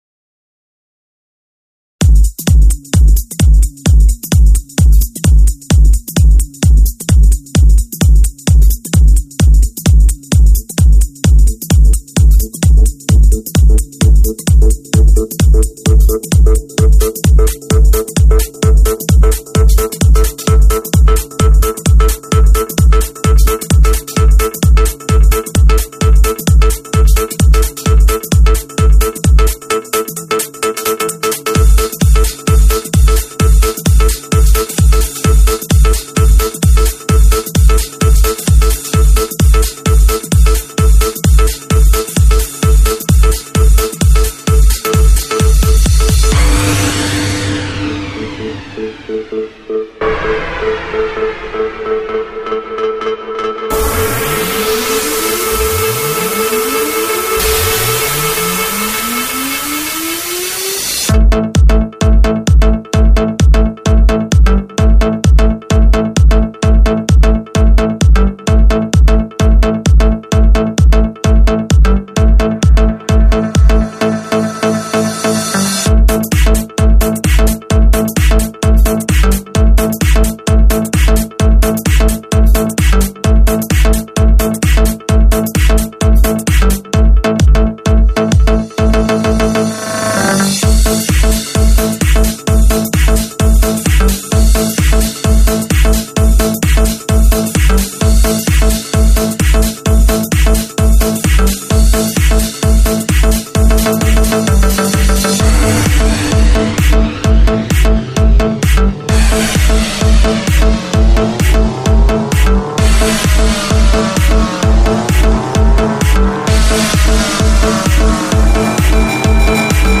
Просто бомба хит (стиль electro house 2007)